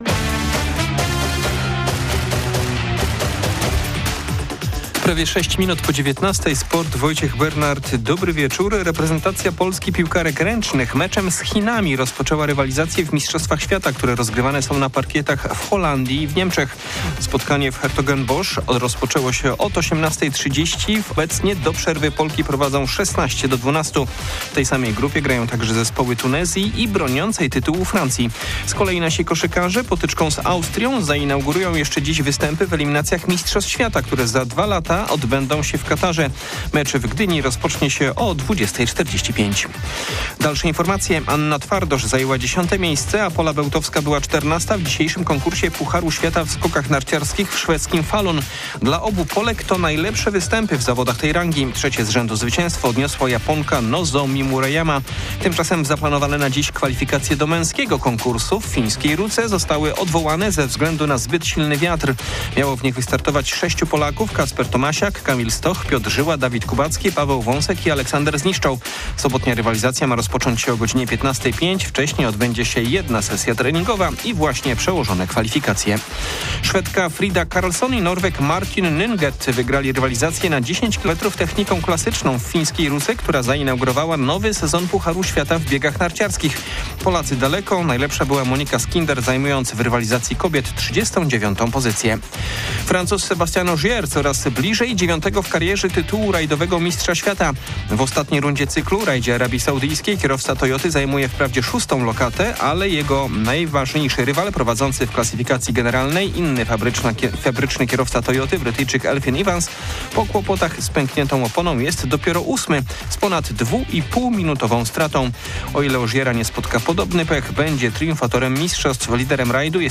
28.11.2025 SERWIS SPORTOWY GODZ. 19:05